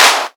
VEC3 Claps 014.wav